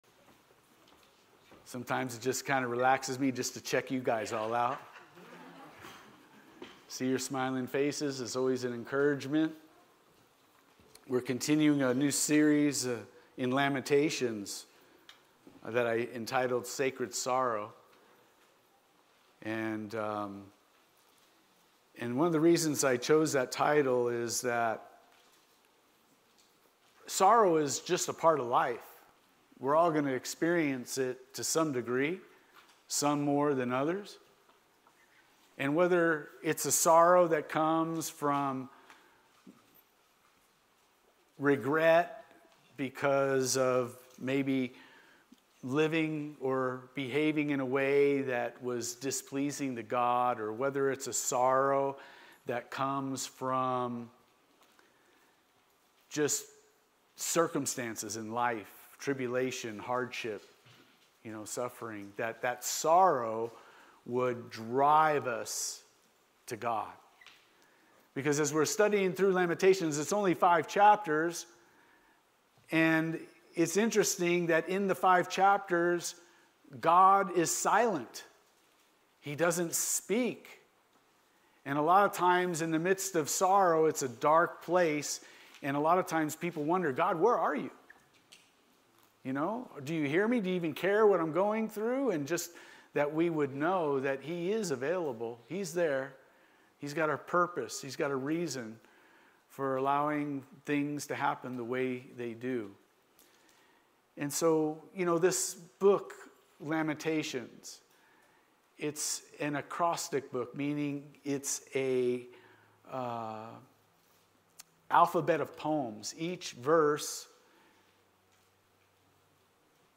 Sermons | Calvary Chapel Pahrump Valley